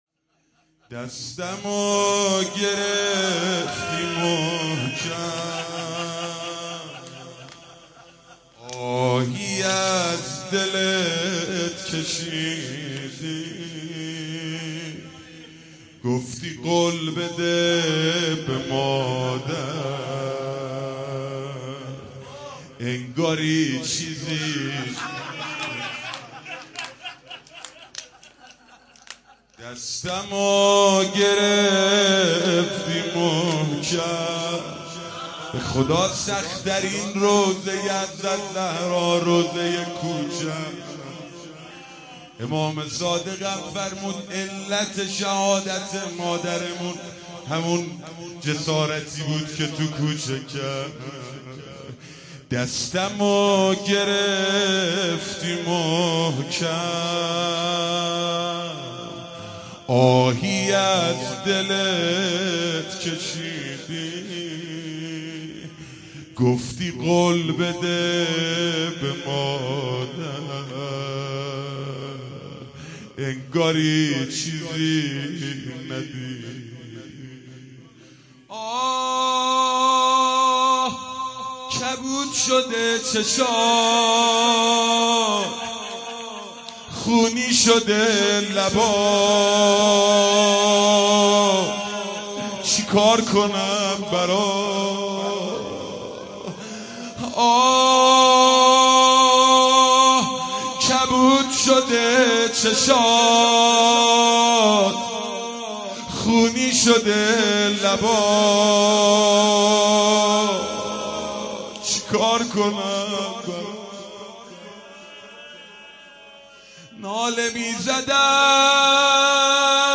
زمزمه احساسی
ویژه ایام فاطمیه